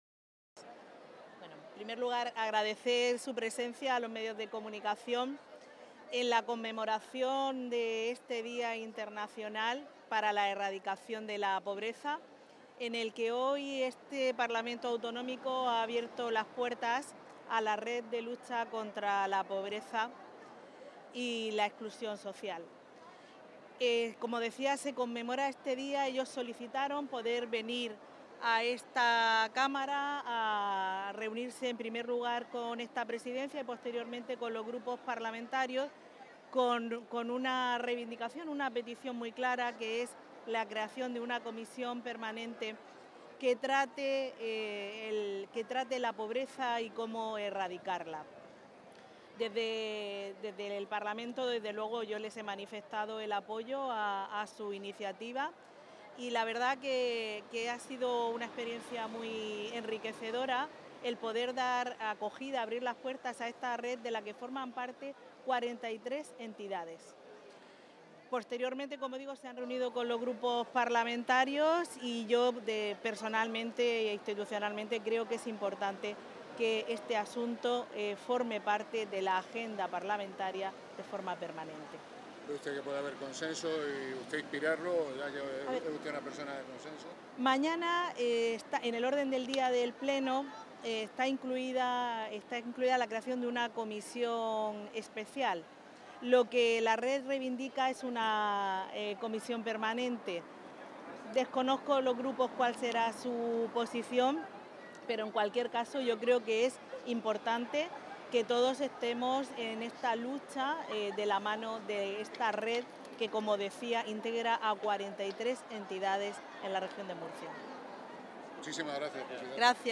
• Declaraciones de Visitación Martínez, presidenta de la Asamblea Regional